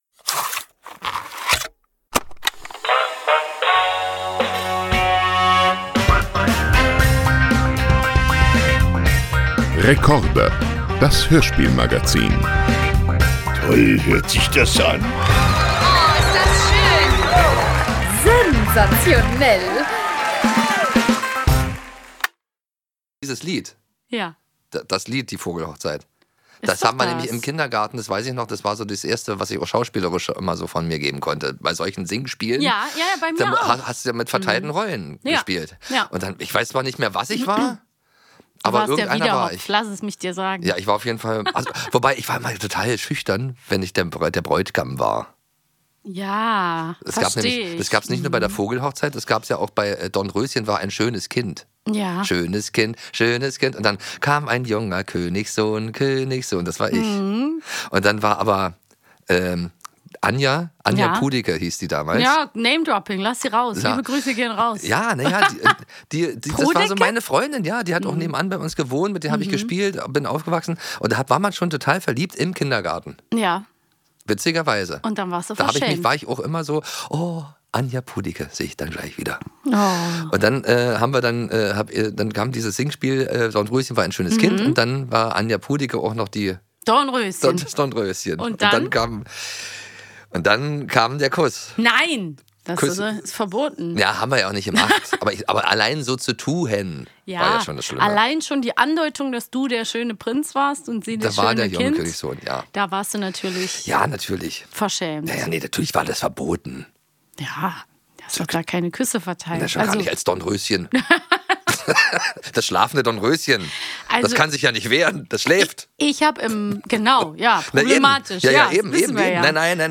zu Gast im Studio